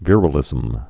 (vîrə-lĭzəm)